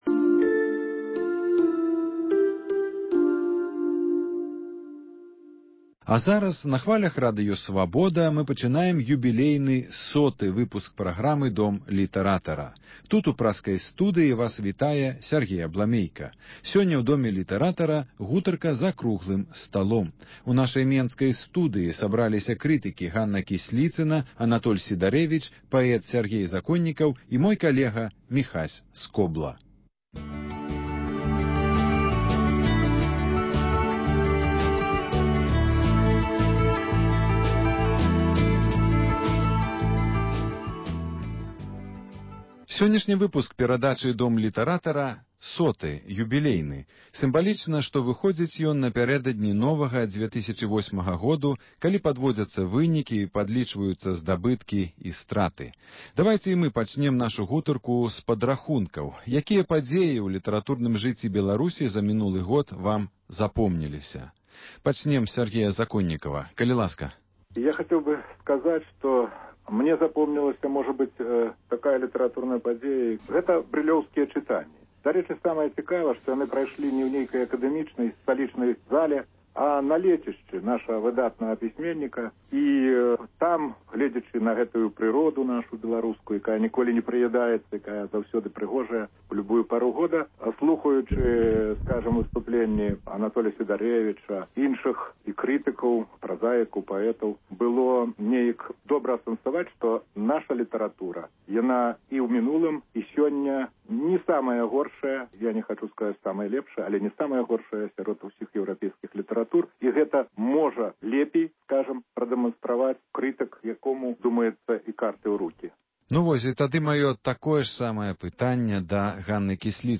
круглы стол